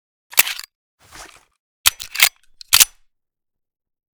tt33_reload_empty.ogg